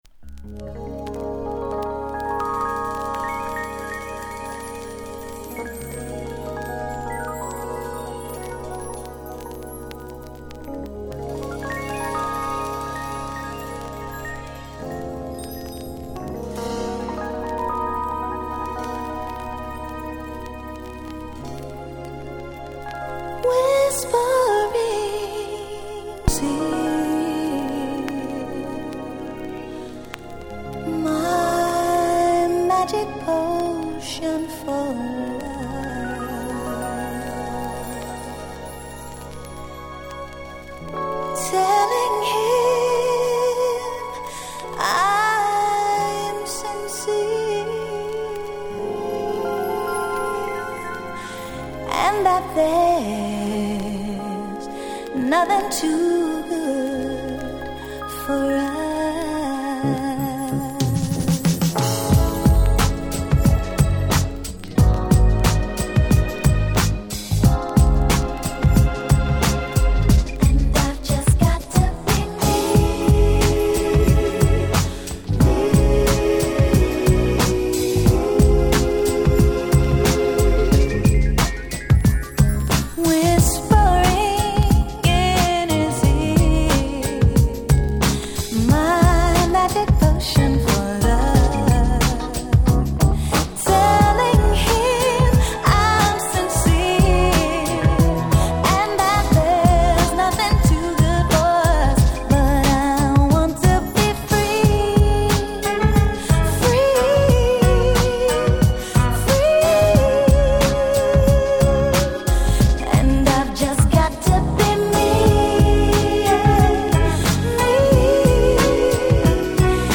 ※試聴ファイルは別の盤から録音してあります。